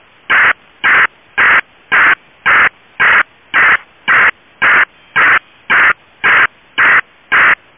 They use a proprietary modem with their own BFSK protocol to transmit information to embassies around the world.